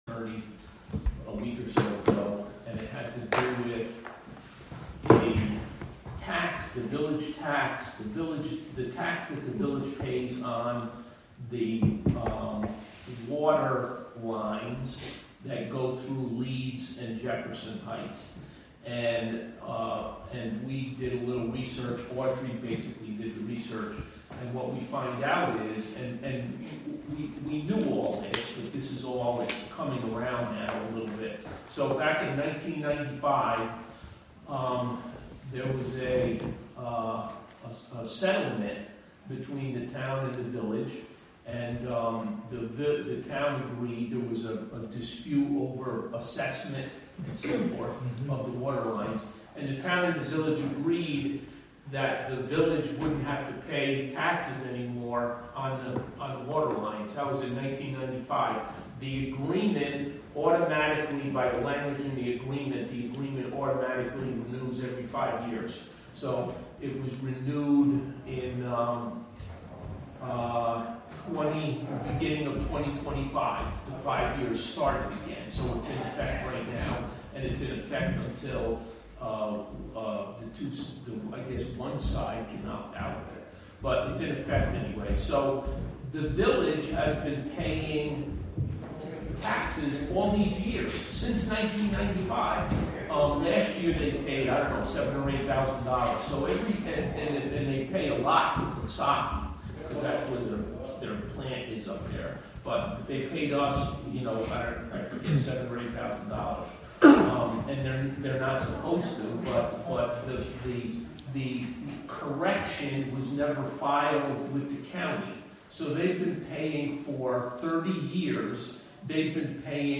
Live from the Town of Catskill: December 17, 2025 Catskill Town Board Meeting Public Hearing w (Audio)